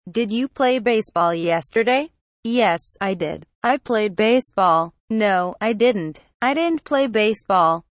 Conversation Dialog #2: